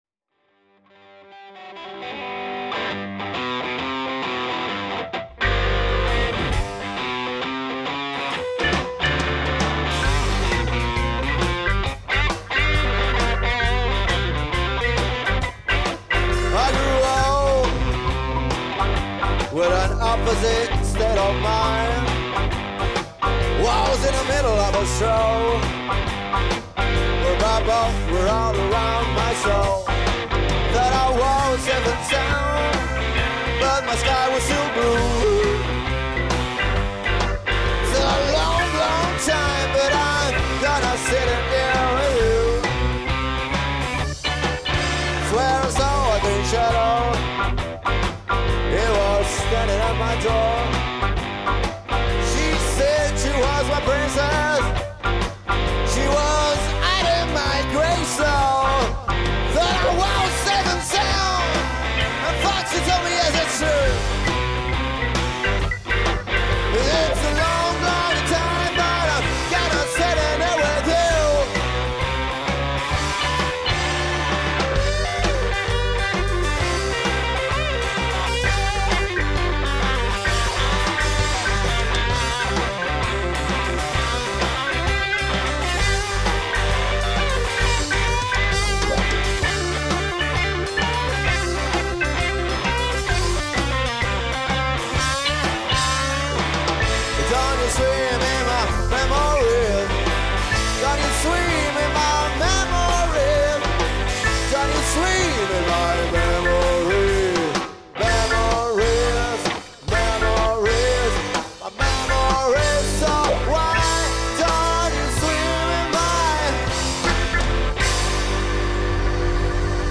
Luogo esecuzionefresh live
GenereBlues